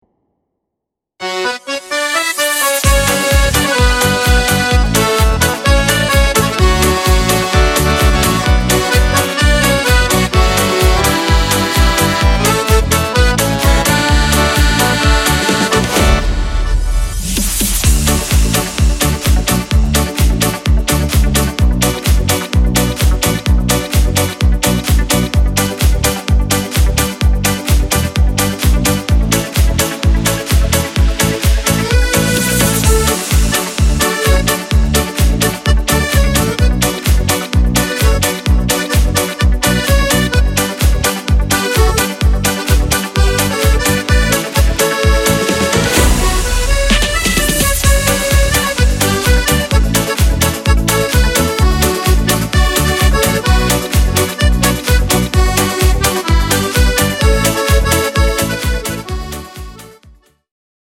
Instrumental
Disco Polo